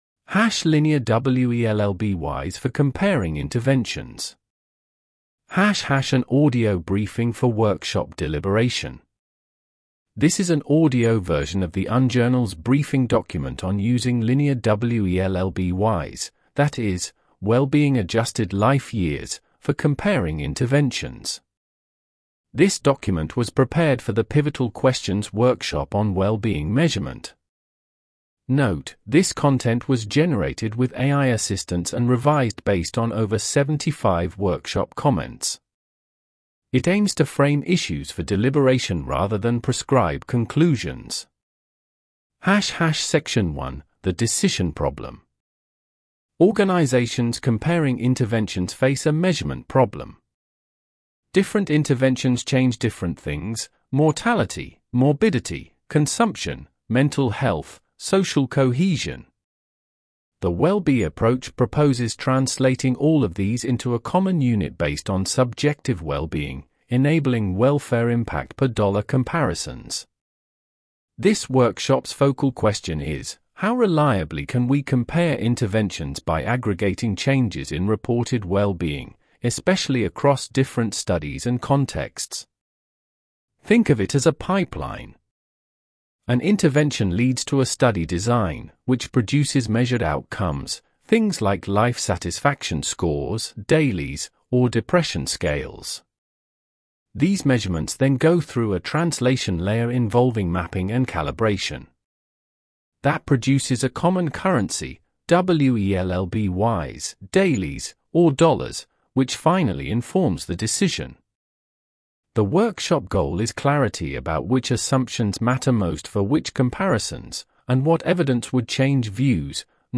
Listen to an audio narration of this page (British academic voice):
Generated with Microsoft Edge TTS (en-GB-RyanNeural)